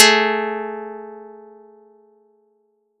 Audacity_pluck_12_13.wav